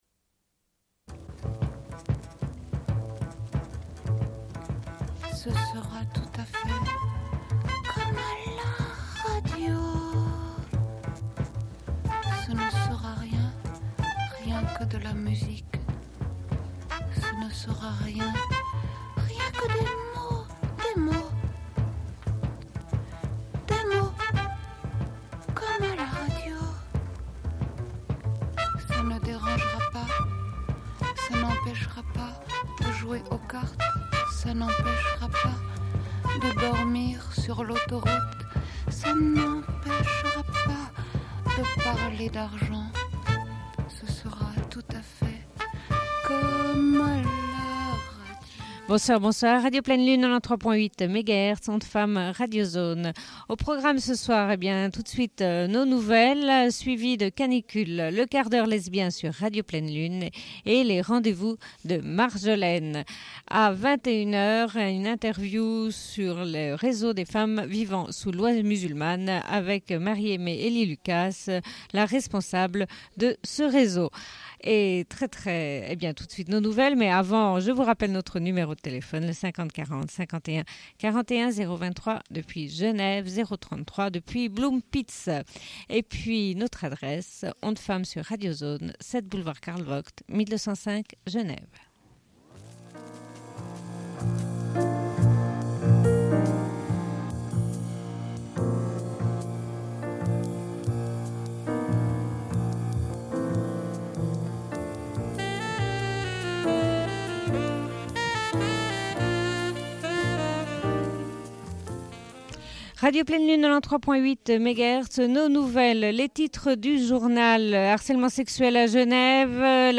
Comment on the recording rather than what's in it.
Une cassette audio, face B28:48